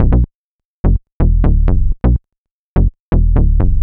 cch_bass_loop_dream_125_F#m.wav